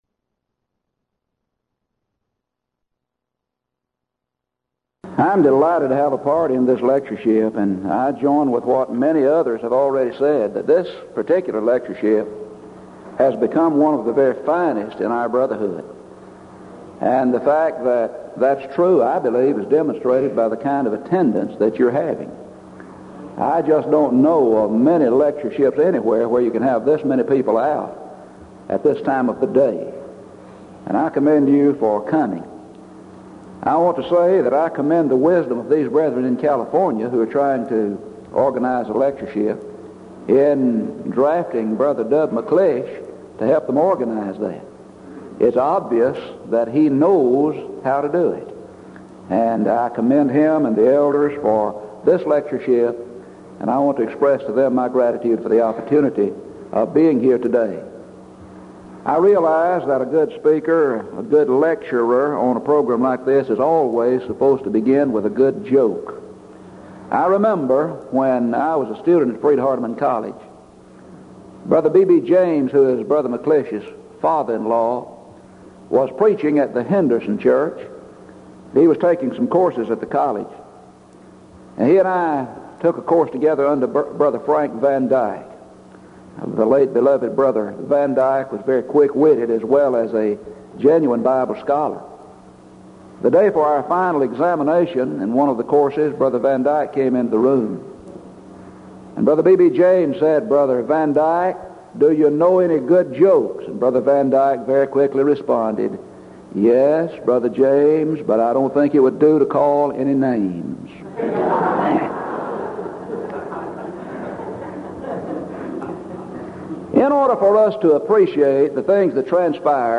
Denton Lectures